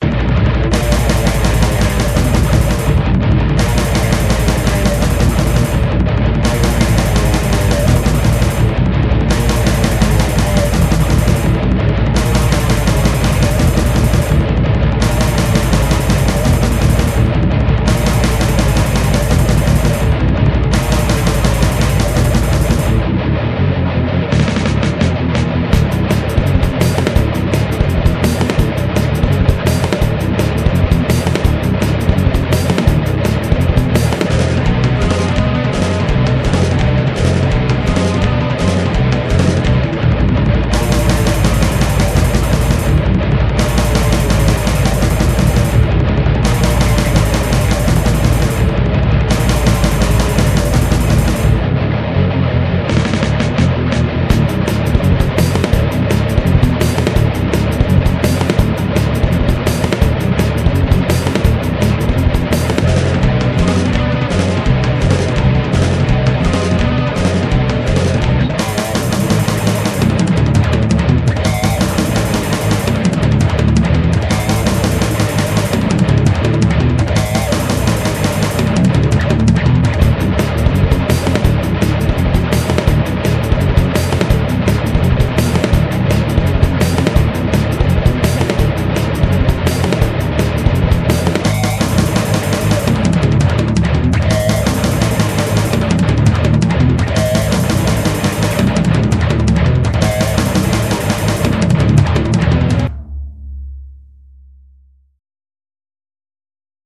いろいろなバンドでボツった曲等々・・・基本的にリズムマシンとギターのみです